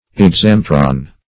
exametron - definition of exametron - synonyms, pronunciation, spelling from Free Dictionary Search Result for " exametron" : The Collaborative International Dictionary of English v.0.48: Exametron \Ex*am"e*tron\, n. [NL.